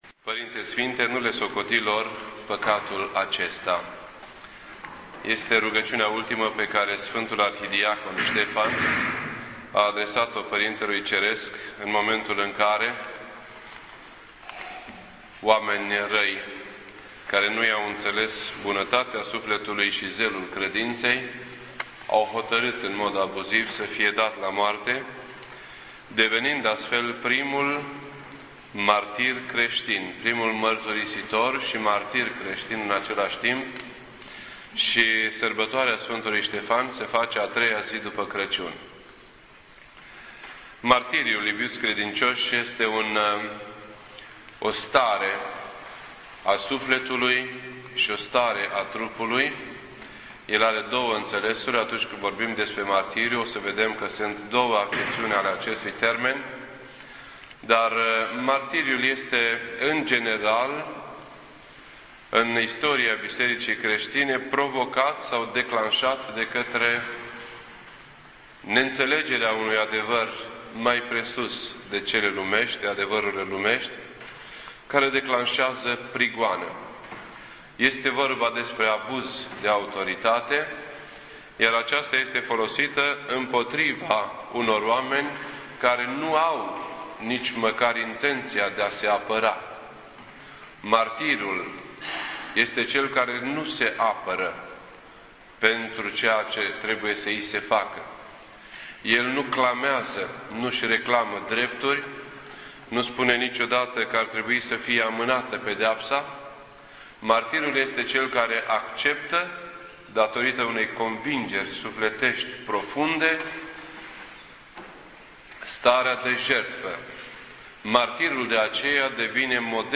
This entry was posted on Saturday, December 27th, 2008 at 9:17 AM and is filed under Predici ortodoxe in format audio.